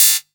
prcTTE47028techno.wav